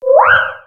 Cri de Chinchidou dans Pokémon X et Y.